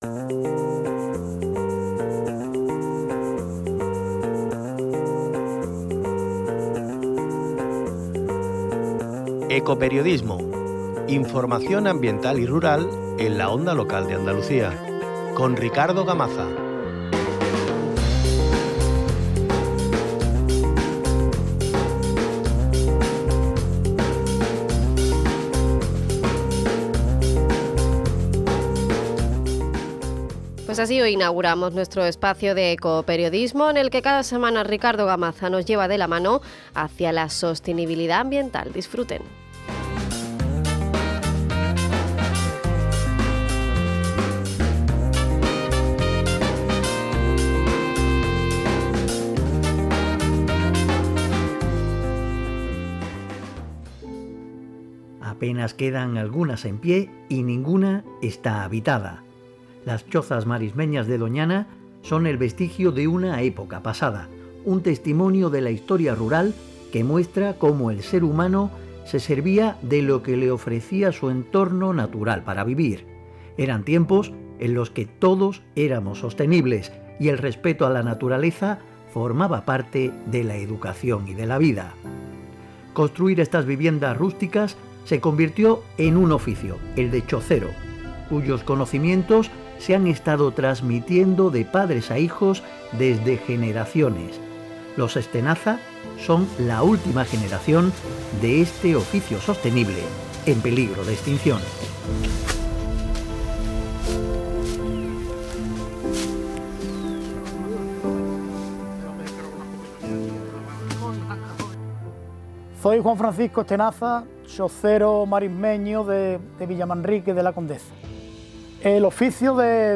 Primera entrega de Ecoperiodismo, espacio dedicado a la información ambiental y rural de Andalucía